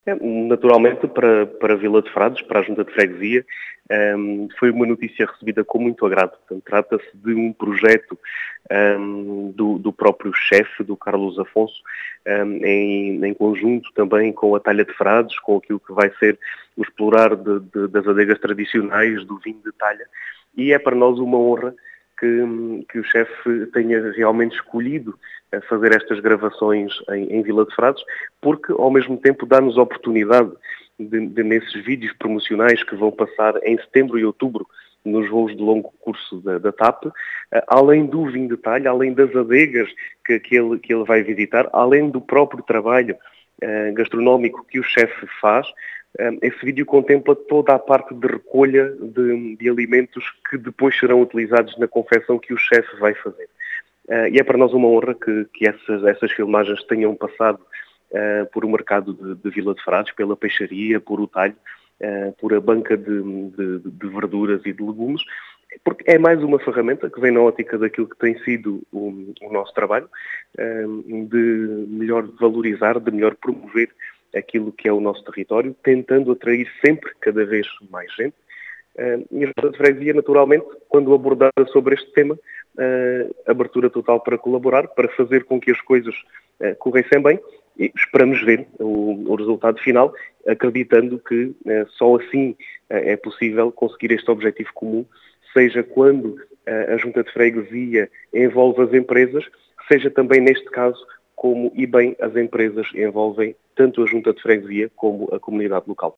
As explicações são do presidente da junta de freguesia de Vila de Frades, Diogo Conqueiro, que fala em mais uma “ferramenta” para a promoção do território.
Diogo-Conqueiro.mp3